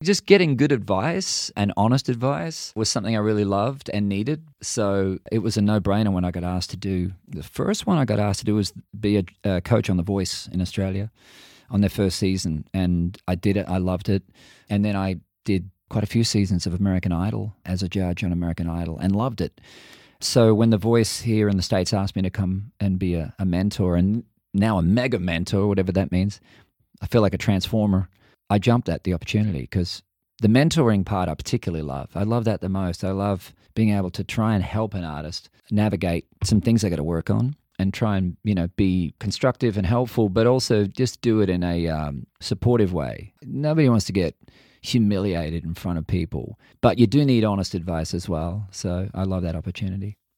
Keith Urban talks about his love of mentoring younger talent in a nice, but honest way and why he jumped at the chance to be the Mega Mentor on NBC's The Voice this season.